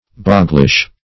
\Bog"glish\